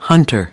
32. hunter (n) /ˈhʌntər/: thợ săn